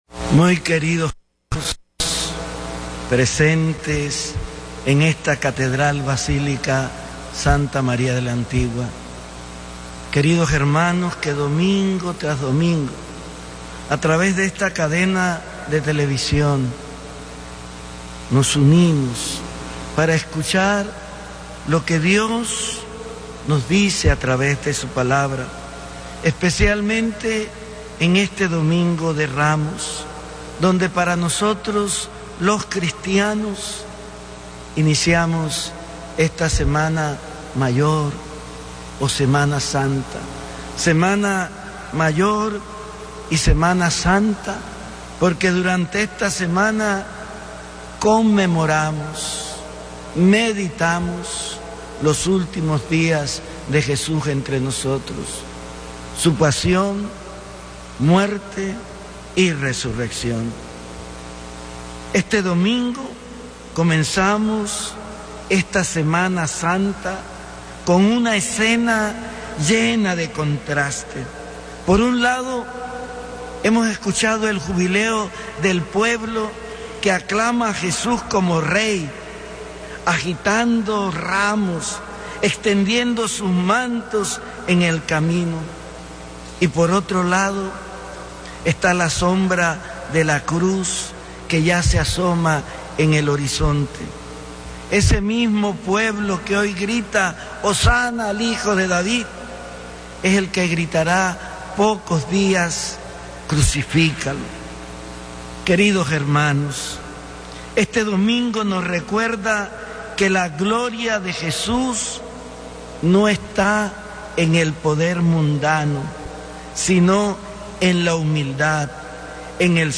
Homilía Domingo de Ramos Mons. José Domingo Ulloa Mendieta OSA - Arquidiócesis de Panamá
Catedral Basílica Santa María de la Antigua, domingo 13 de abril 2025